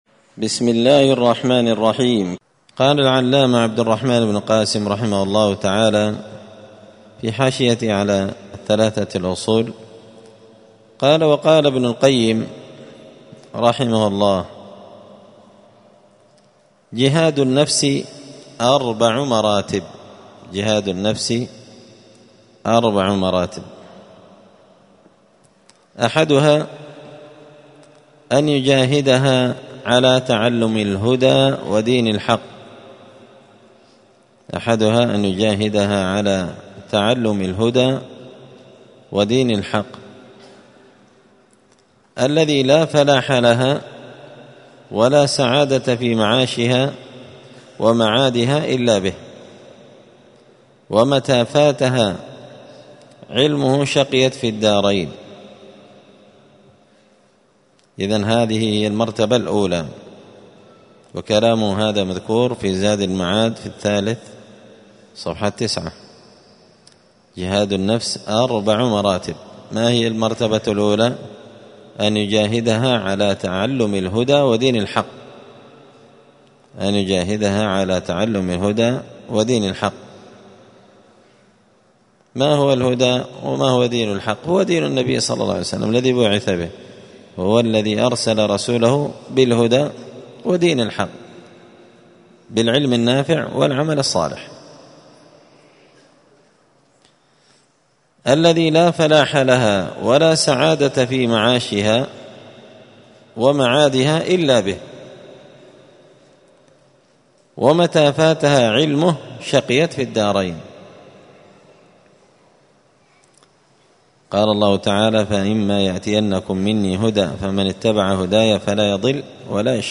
*الدرس الخامس (5) من قوله {قال الإمام الشافعي رحمه الله لوما أنزل حجة على…}*